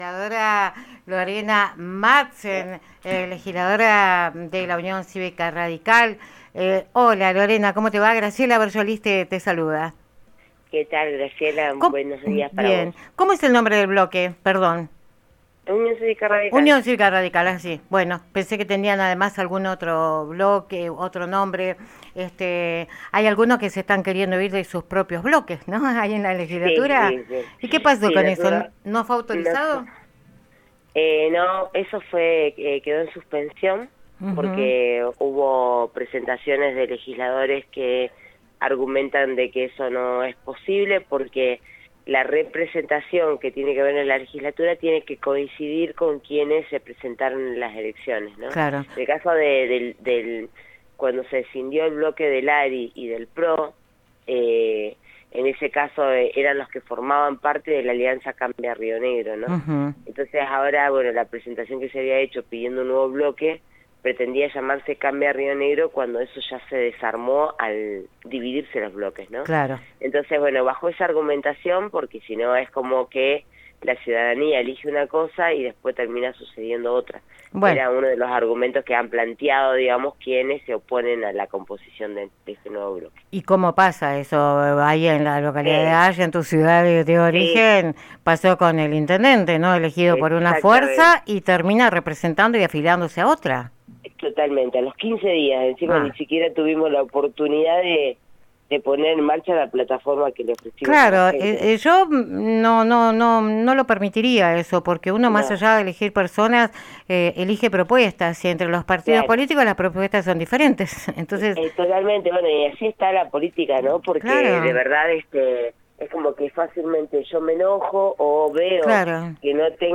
Entrevista a Lorena Matzen, legisladora UCR. 03 de marzo 2026